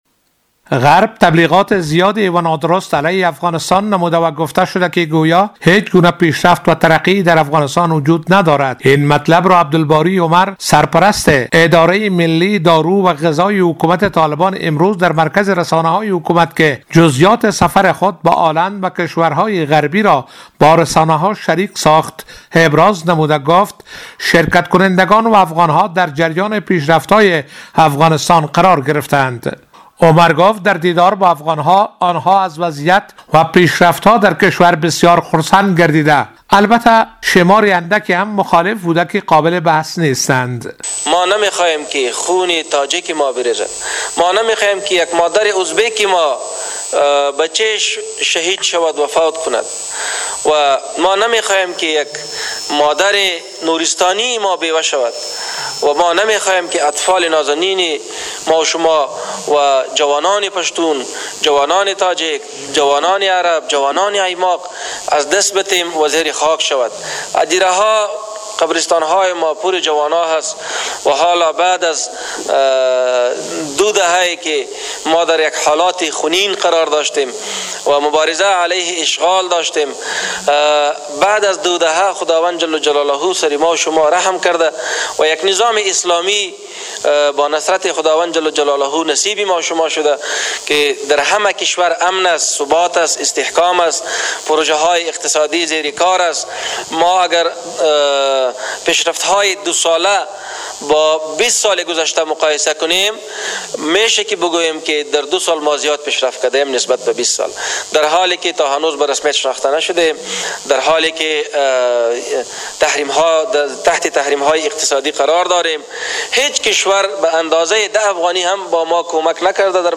کنفرانس مطبوعاتی درباره جزئیات سفر اخیر سرپرست اداره ملی ادویه و غذا به کشور هلند امروز (دوشنبه، ۲۹ عقرب) با حضور نمایندگان رسانه‌های ملی و بین‌المللی در م...